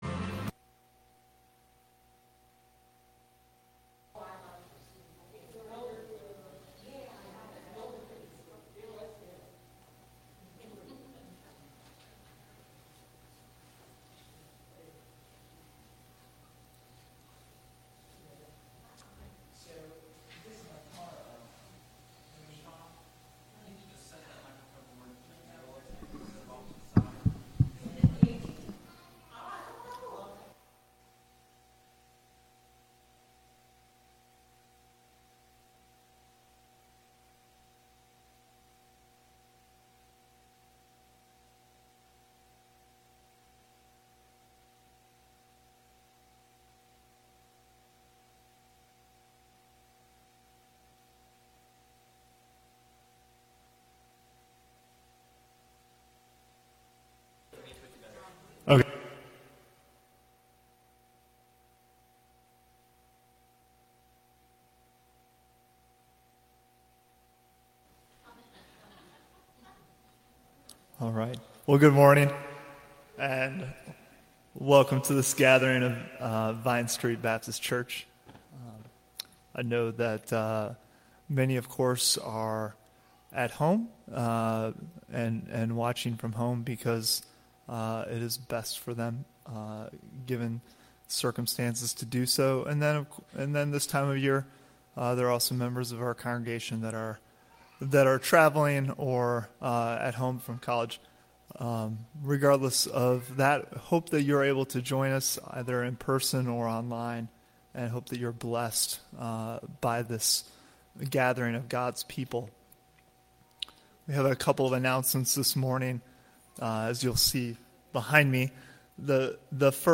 Morning Worship
July 12 Worship Audio Bible References Luke 6:43